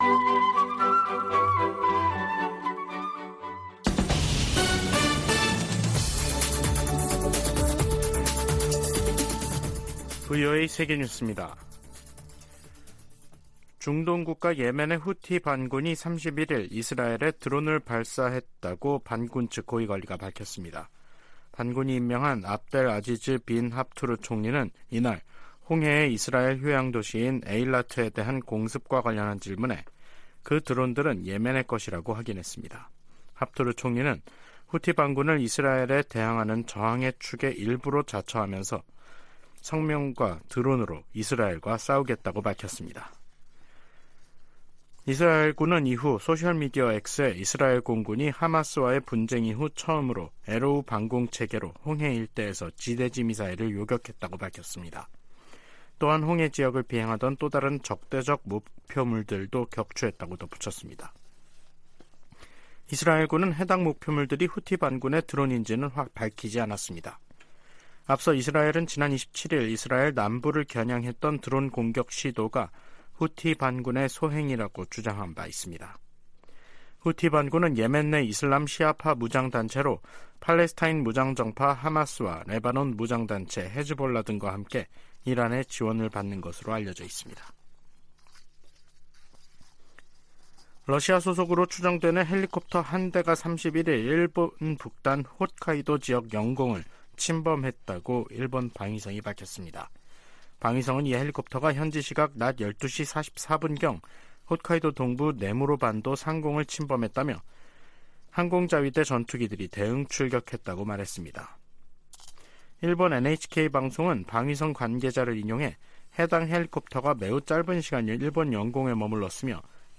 VOA 한국어 간판 뉴스 프로그램 '뉴스 투데이', 2023년 10월 31일 3부 방송입니다. 미 국무부 대북특별대표가 중국 한반도사무 특별대표와 화상회담하고 북러 무기거래가 비확산 체제를 약화시킨다고 지적했습니다. 하마스가 북한제 무기를 사용했다는 정황이 나온 가운데 미 하원 외교위원장은 중국·이란에 책임을 물어야 한다고 주장했습니다. 북한의 핵 위협에 대한 한국 보호에 미국 핵무기가 사용될 것을 확실히 하는 정책 변화가 필요하다는 보고서가 나왔습니다.